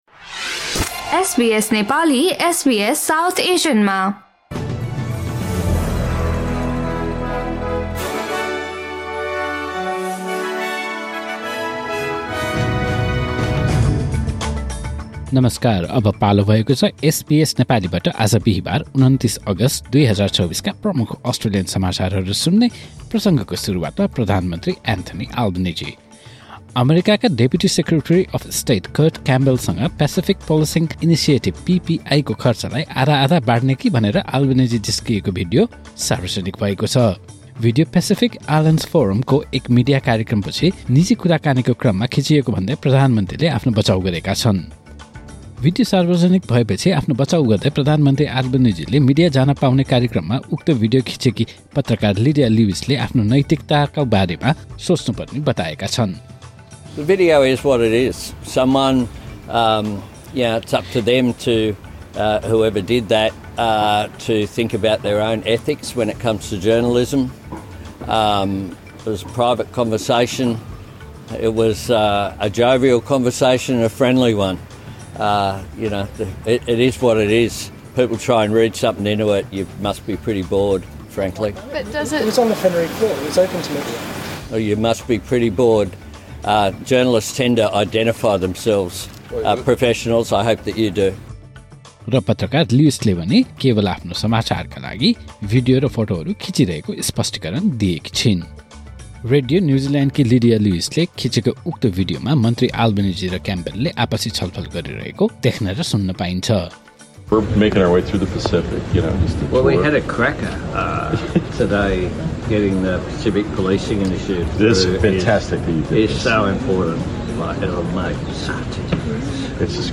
SBS Nepali Australian News Headlines: Thursday, 29 August 2024